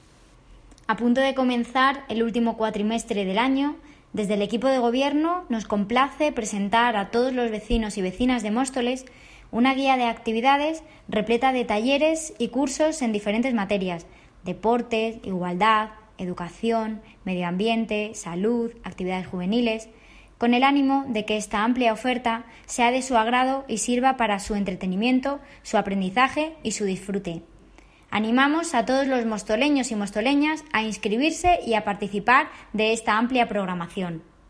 Jessica Antolín (Concejala de Desarrollo Económico, Empleo y Nuevas Tecnologías) sobre Guía de Actividades